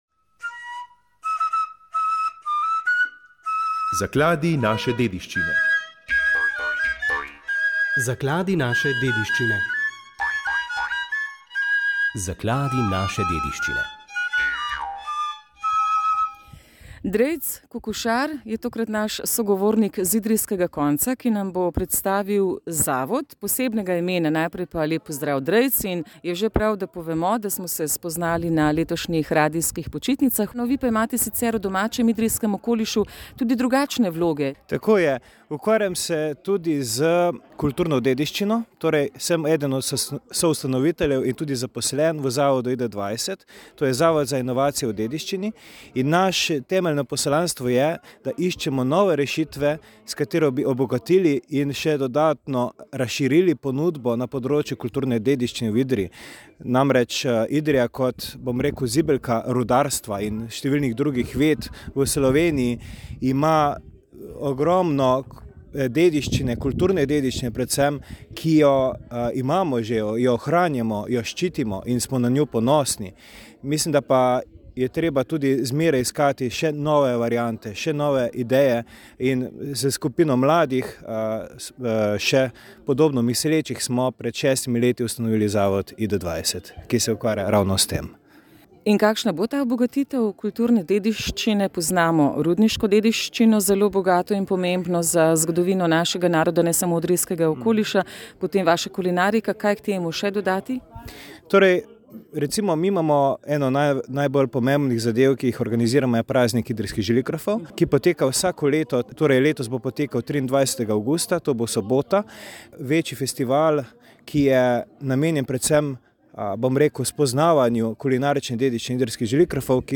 Pred slovenskim kulturnim praznikom, ob prihajajočem 80 rojstnem dnevu in pred podelitvijo najvišjih državnih priznanj za delovanje na umetniških področjih, je bil v oddaji »Naš gost« letošnji Prešernov nagrajenec za življenjsko delo, akademski slikar in pedagog Herman Gvardjančič! Z njim smo govorili o umetnosti in ustvarjanju o pedagoškem delu in tudi o praznovanju praznika kulture, kajti Slovenci smo verjetno edini narod, ki imamo ob tem prazniku tudi dela prost dan …